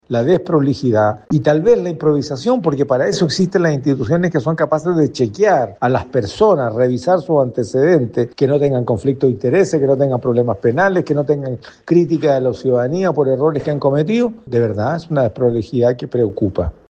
El senador de la Democracia Cristiana, Iván Flores, acusó desprolijidad del Gobierno y pidió chequear los antecedentes antes, para “no cometer un error tras otro”.